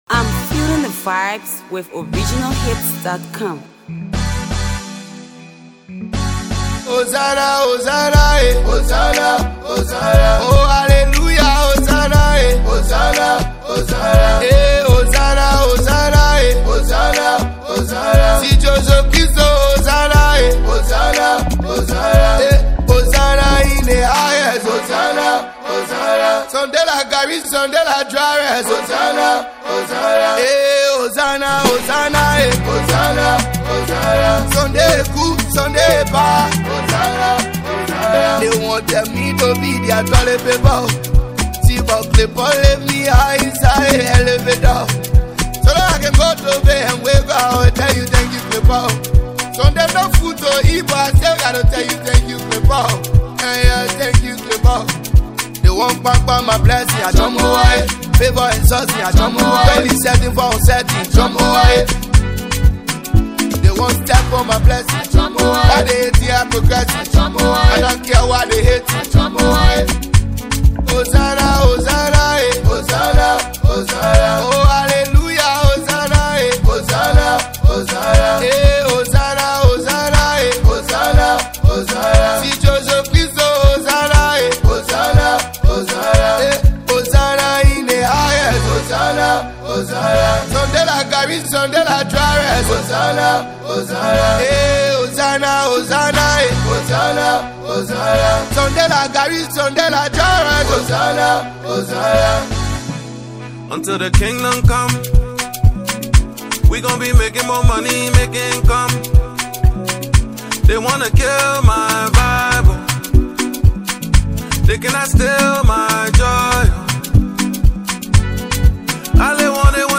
AfroAfro PopFeaturedLATEST PLAYLISTMusic